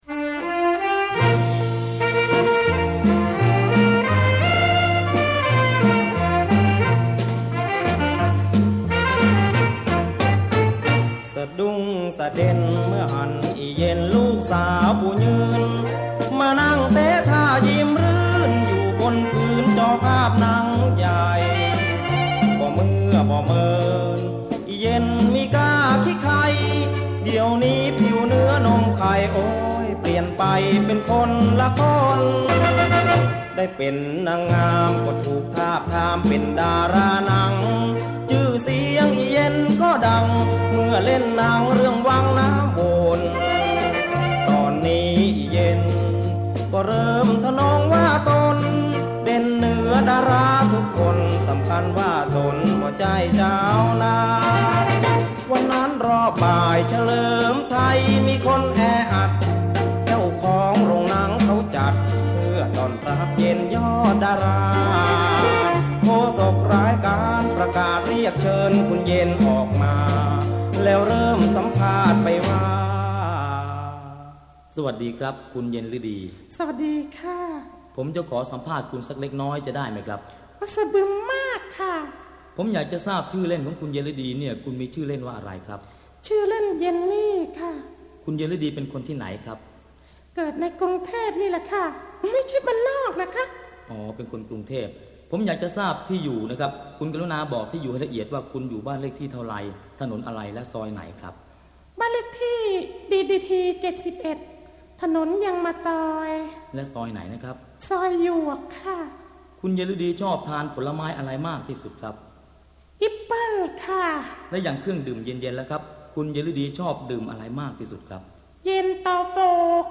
เพลงคำเมือง